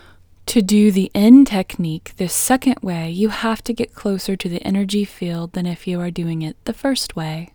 IN – the Second Way – English Female 5